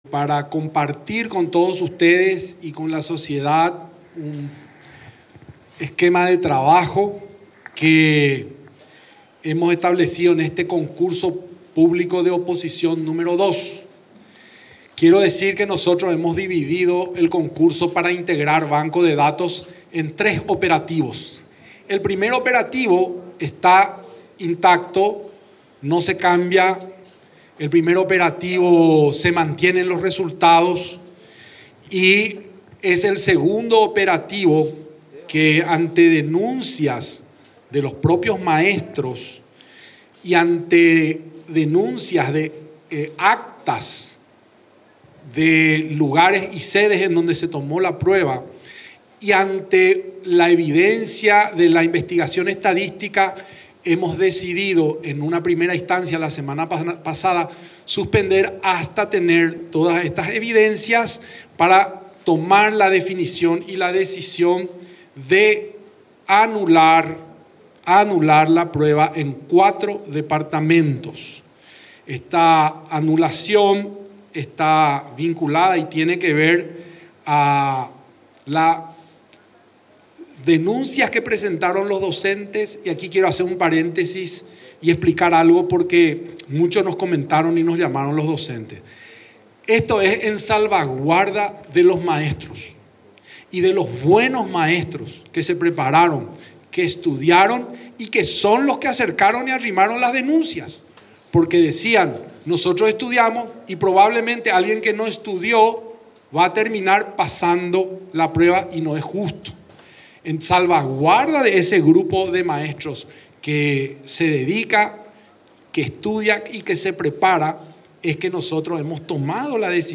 Mediante una conferencia de prensa brindada en la tarde del lunes, el ministro de Educación y Ciencias (MEC), Luis Ramírez, dio a conocer sobre la determinación de anular los exámenes docentes en cuatro regiones del país que son en San Pedro, Canindeyú, Guairá y Cordillera.